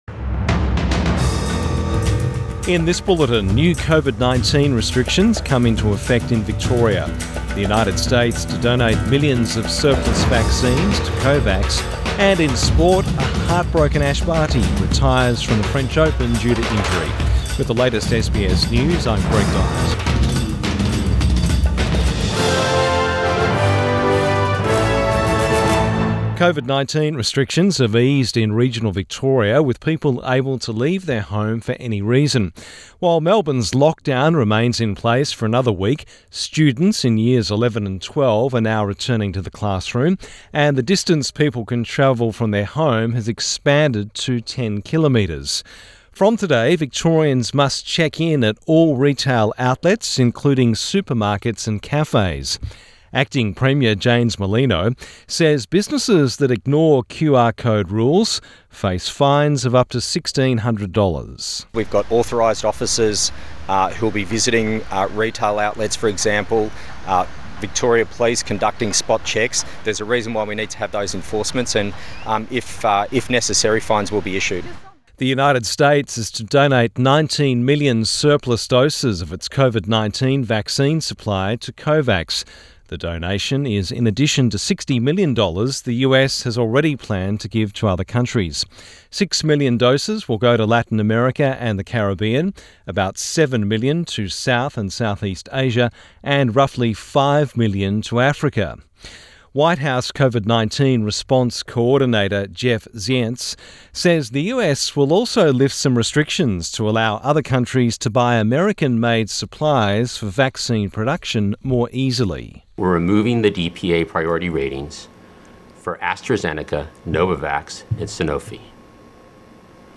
AM bulletin 4 June 2021